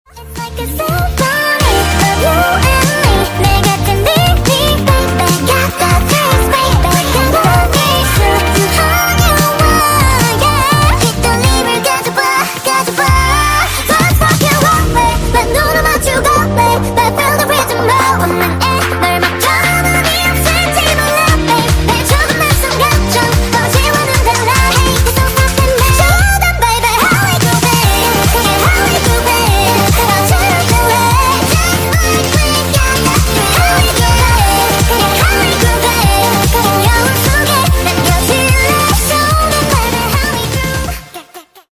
(sped up)